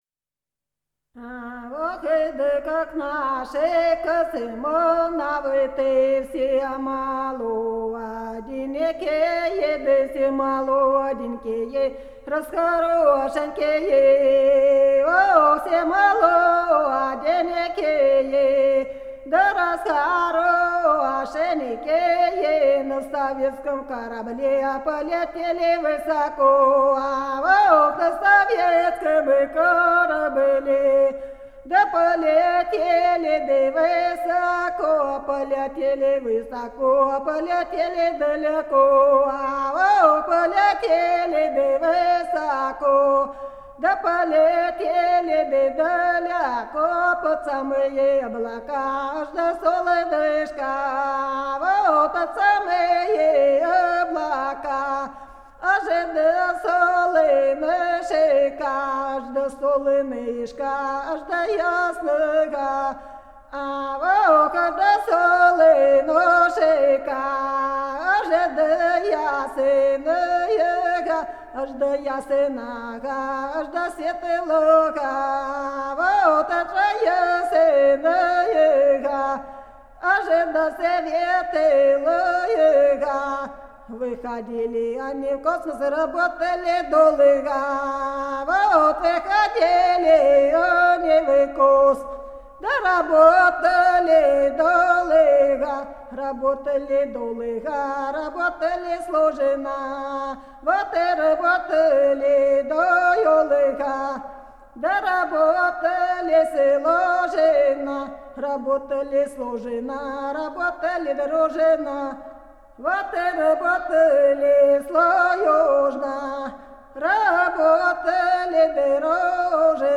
Образец советского сказительского искусства.